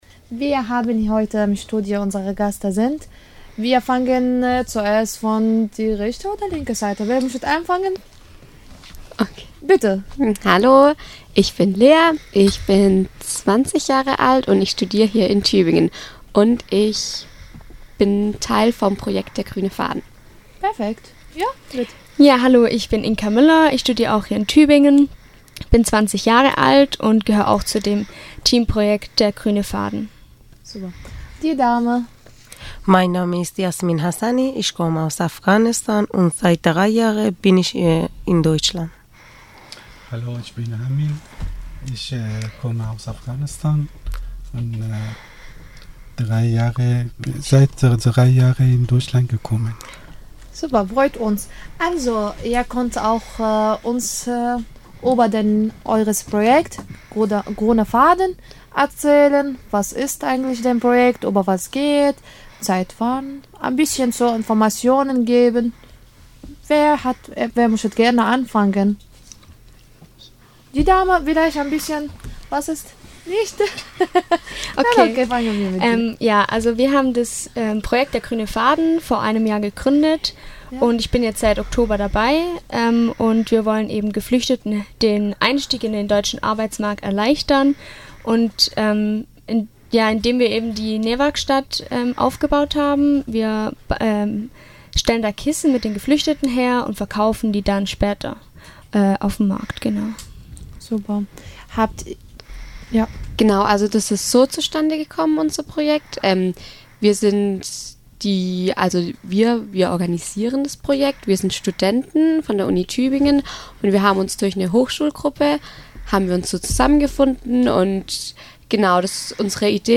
69326_Der_Gruene_Faden_Interview.mp3